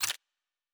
pgs/Assets/Audio/Sci-Fi Sounds/Weapons/Weapon 05 Foley 2 (Laser).wav at master
Weapon 05 Foley 2 (Laser).wav